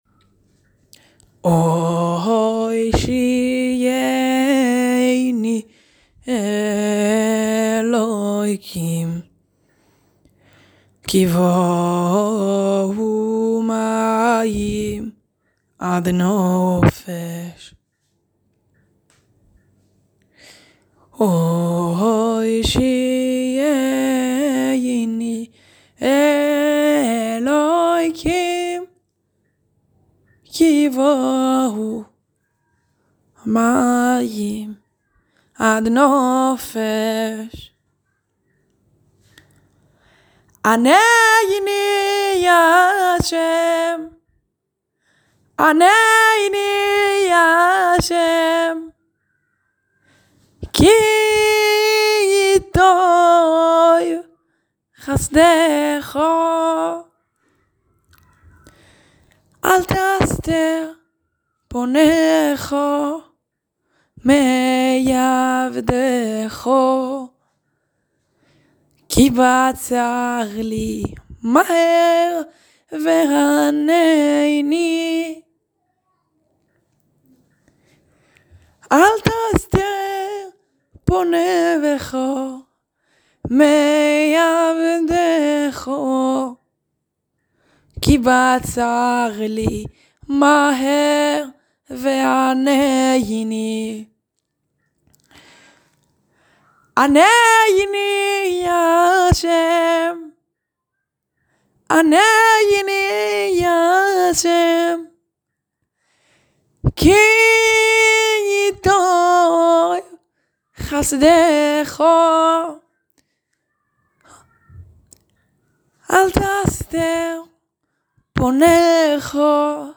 73 BPM